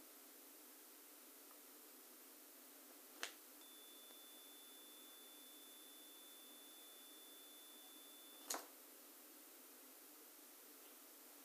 записано на MKH-416 с расстояния 10 см. расстояние от мыши до уха во время работы 30-40 см. основной тон 3500 Гц. сначала звук комнаты, потом включаю мышь в USB.